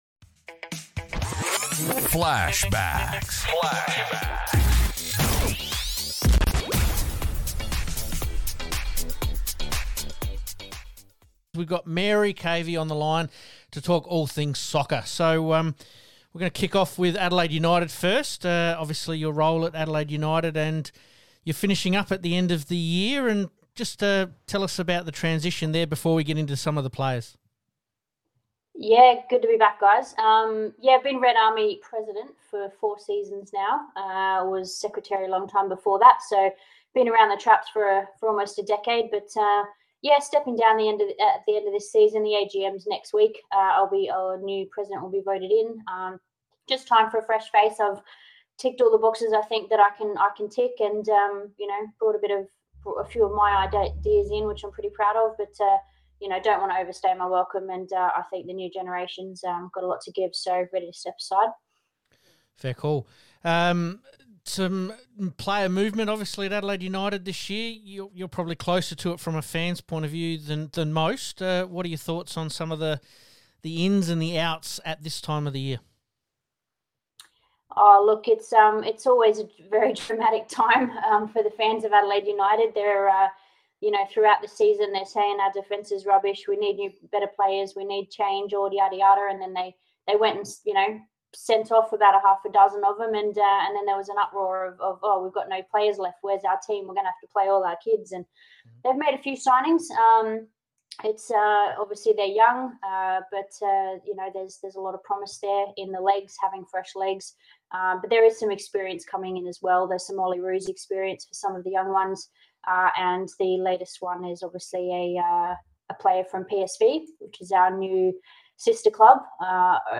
FLASHBACKS - Re-Live some of our Interviews (only) with some of our special guests